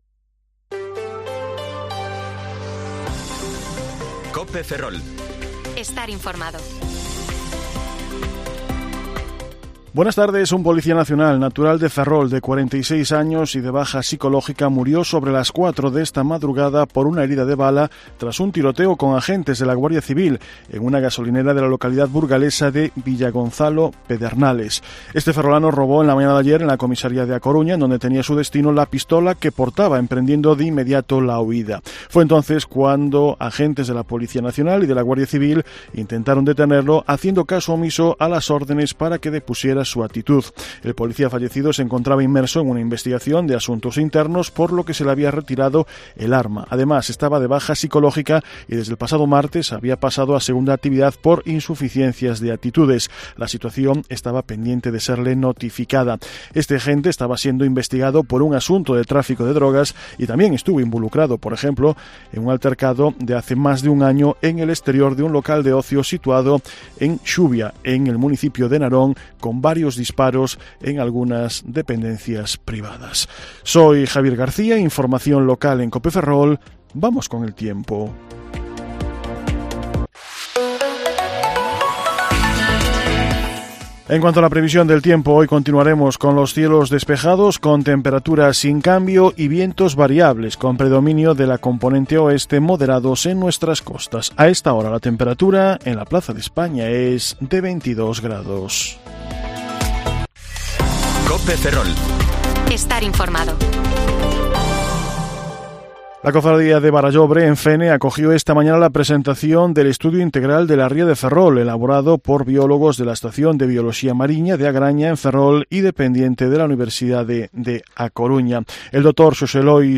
Informativo Mediodía COPE Ferrol 19/4/2023 (De 14,20 a 14,30 horas)